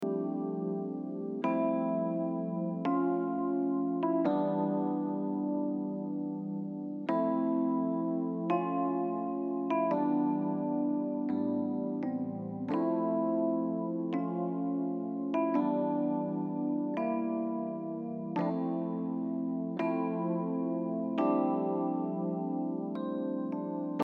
リバーブをかけると部屋の大きさを表現できるとともに音を奥に配置することができるので、この後手前にくるボーカルのスペースを空けることができます。
といってもエレピのトラック+ディレイ＆リバーブだけを聴いてみると結構リバーブがかかっていますが、アンサンブルの中に入るとそんなに目立たないですよね。